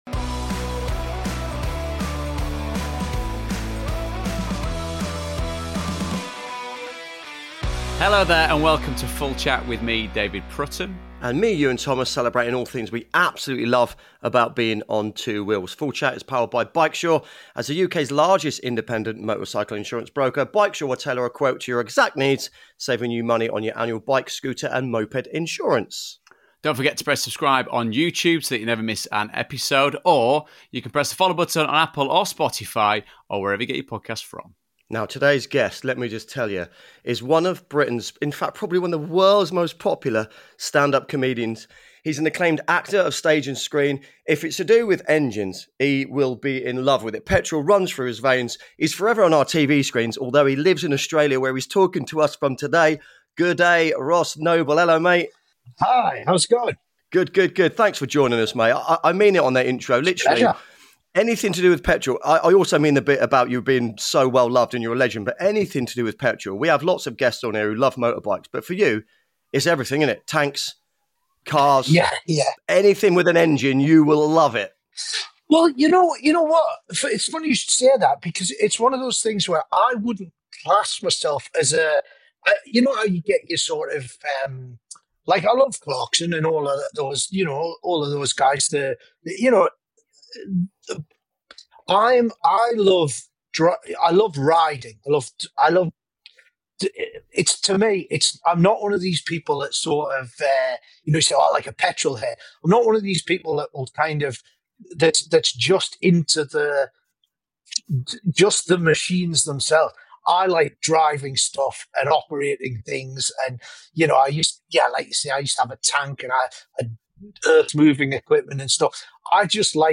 Live from his home in Australia, Ross Noble talks sharks, tours, tanks and of course his passion for collecting motorbikes and keeping them away from his wife!